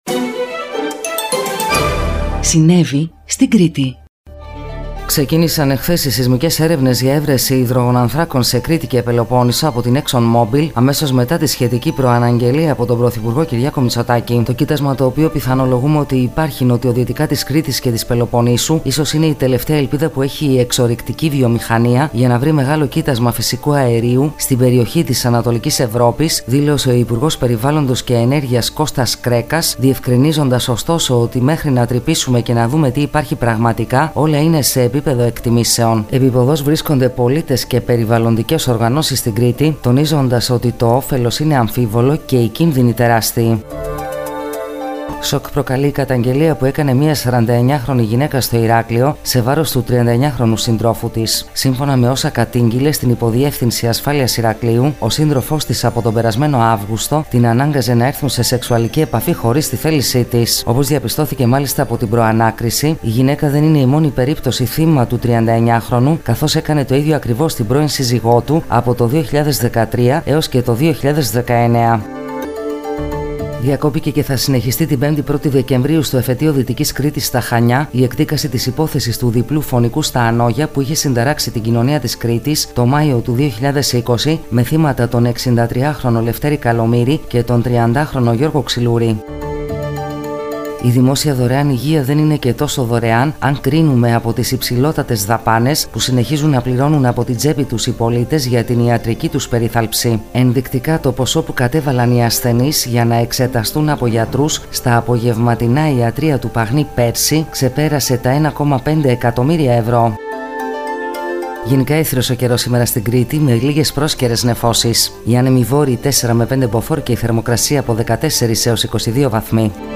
Γυναικεία Εκφώνηση – “ Συνέβη…